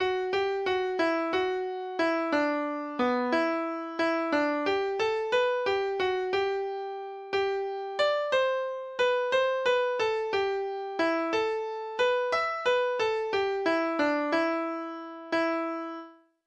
last ned nota Lytt til data-generert lydfil Forsiktig og slu er kattepus.